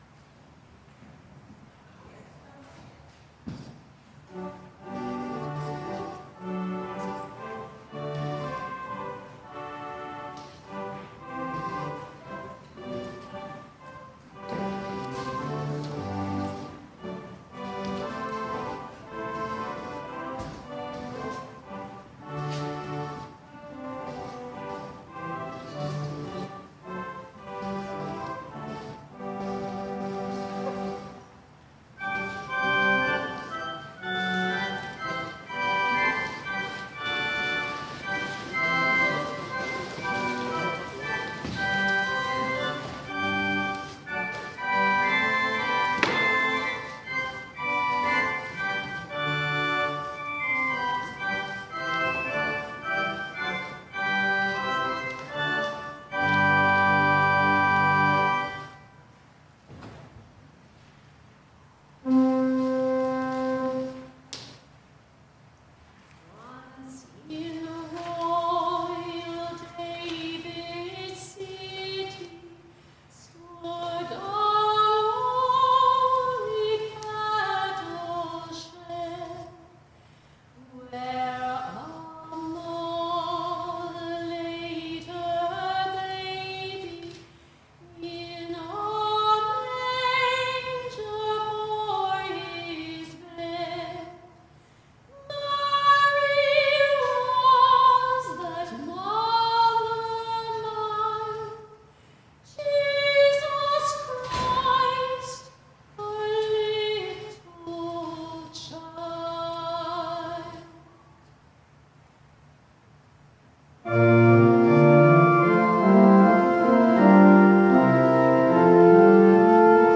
Worship and Sermon audio podcasts
WORSHIP - 4:00 p.m. Christmas Lessons and Carols